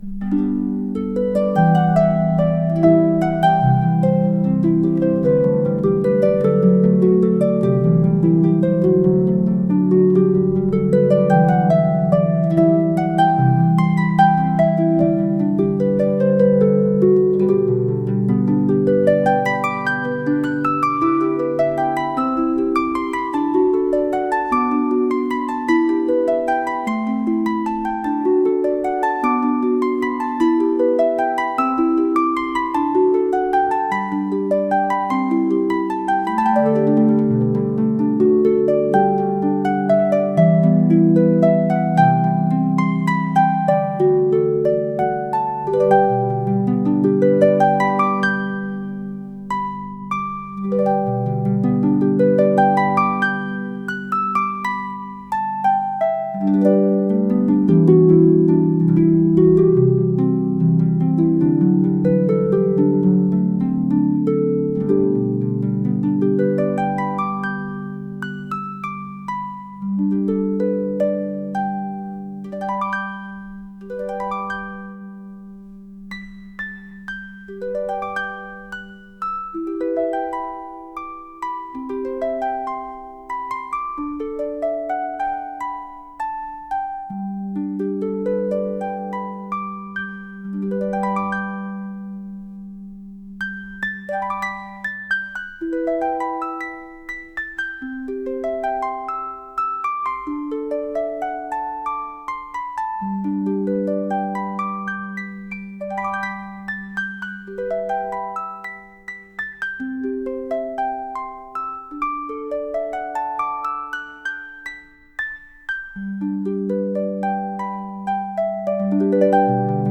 calm Music